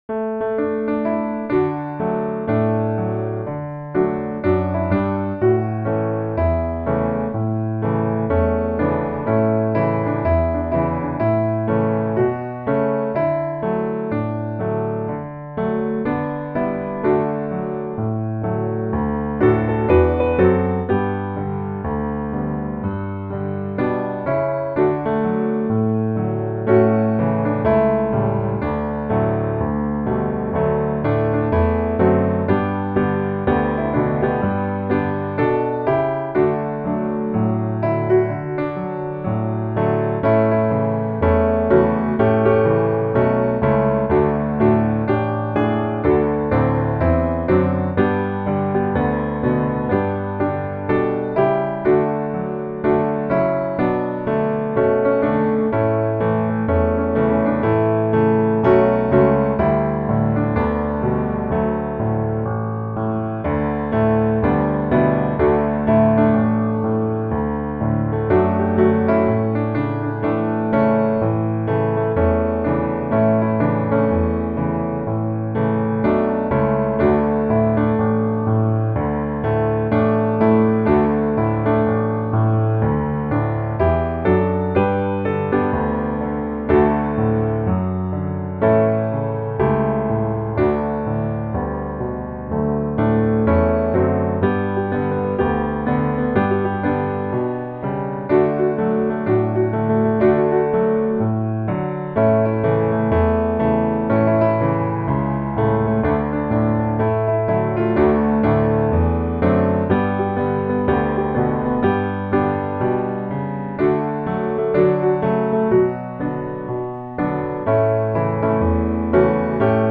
(Both parts can be sung together)
D Dur